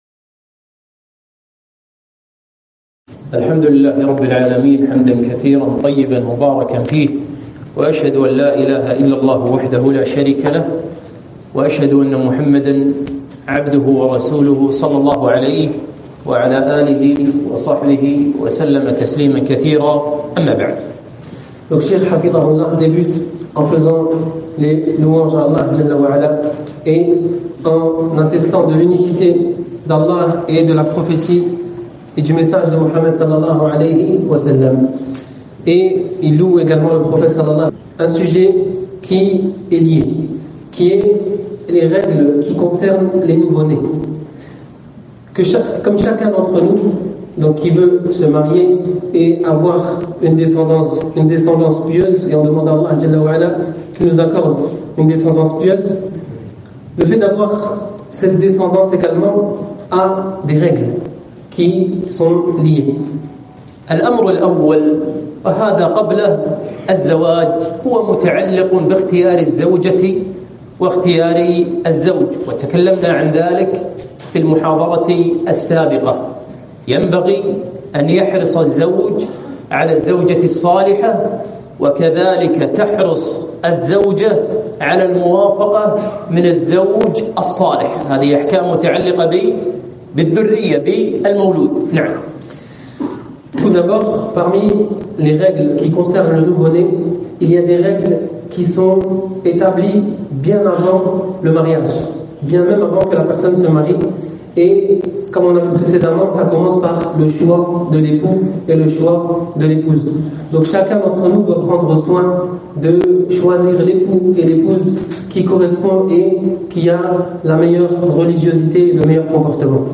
محاضرة - أحكام المولود (مترجمة للفرنسية)